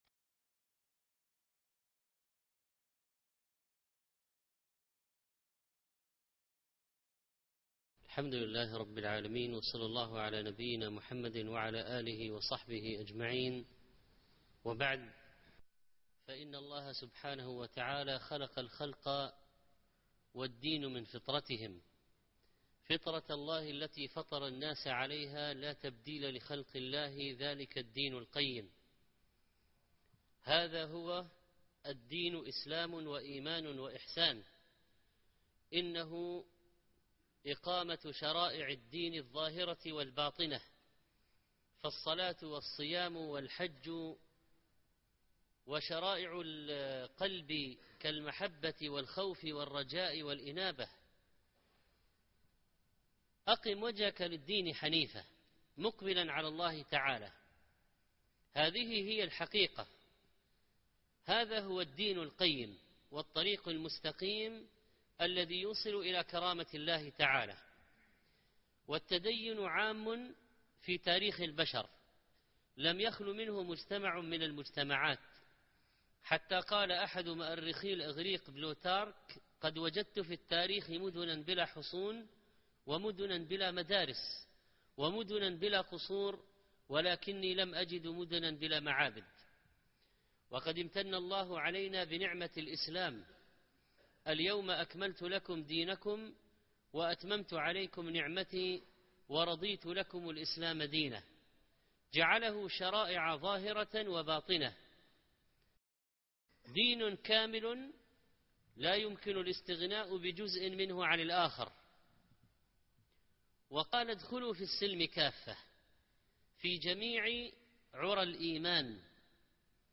الدين ليس مظهرا فقط ( 1428 /شوال/ 13) -محاضرة - الشيخ محمد صالح المنجد